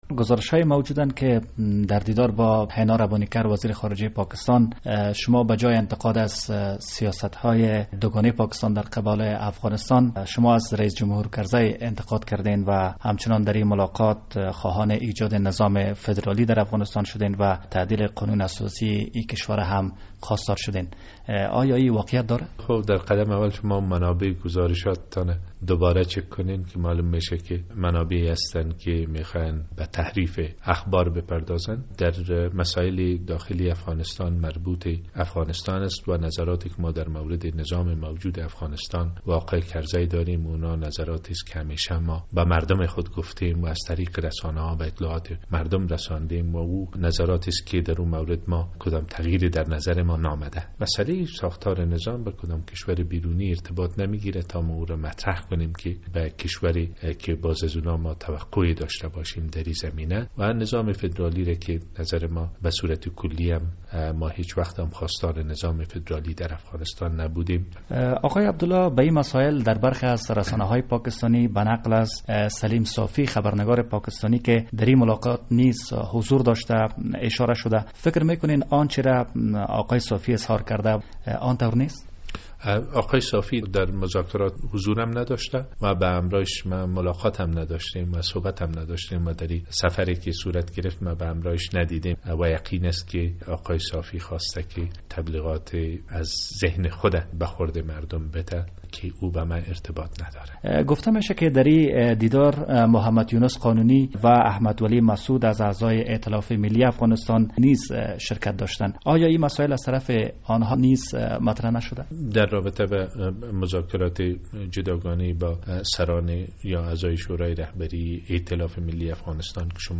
مصاحبه با داکتر عبدالله در مورد ملاقات با صدراعظم پاکستان